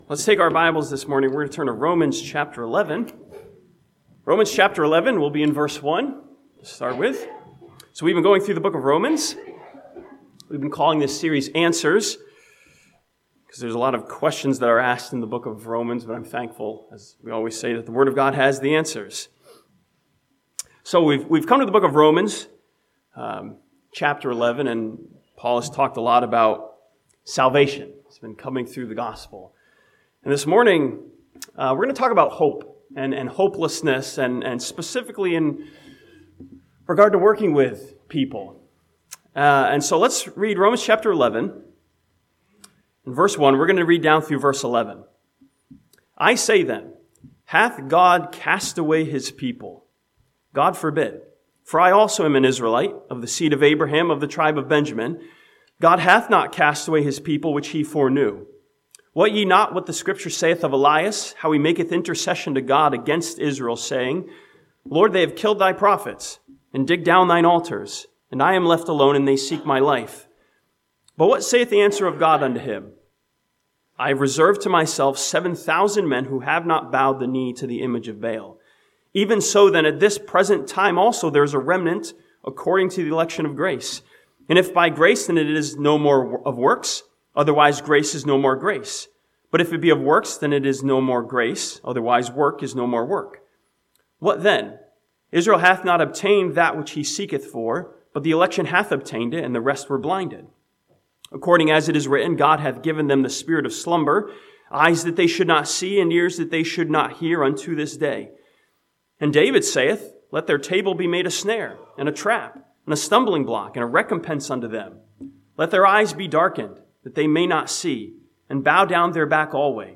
This sermon from Romans chapter 11 ask a question of hopelessness, "is it all over?" and finds hope in the grace and working of God.